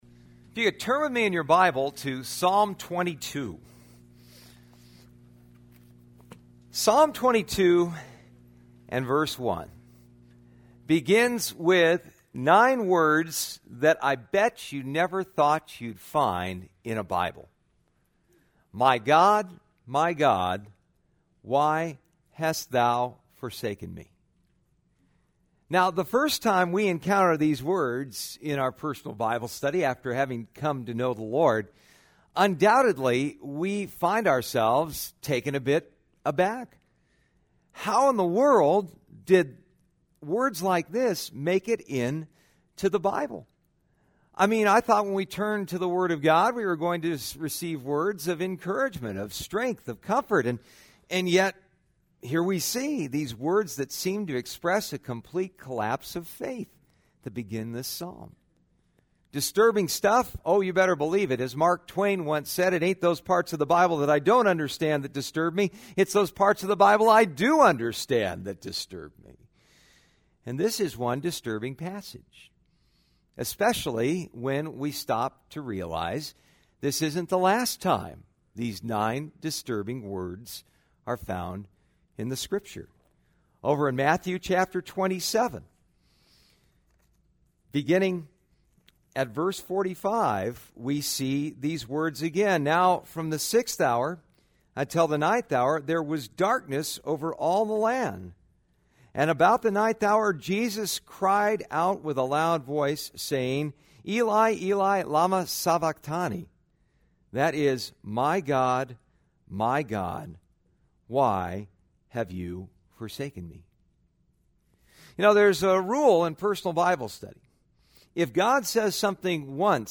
Passage: Psalm 22 Service Type: Sunday Morning